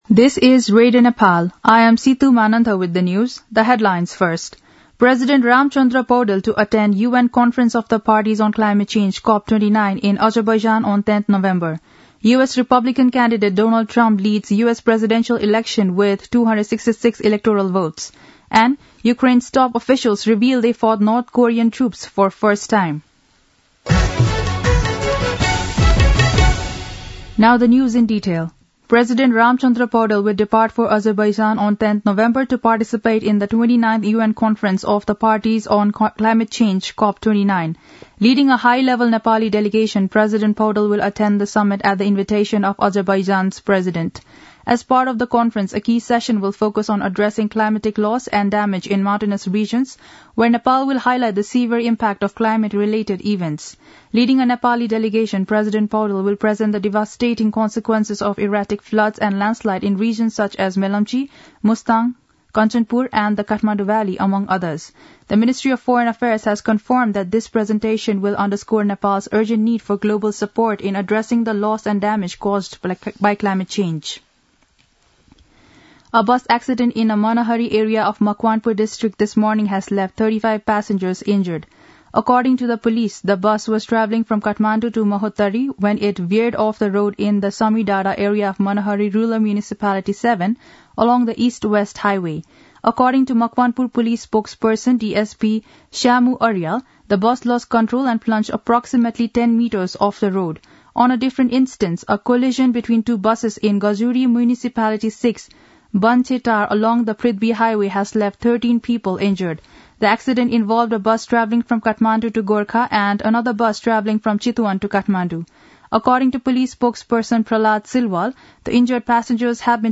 दिउँसो २ बजेको अङ्ग्रेजी समाचार : २२ कार्तिक , २०८१
2-pm-english-news-1.mp3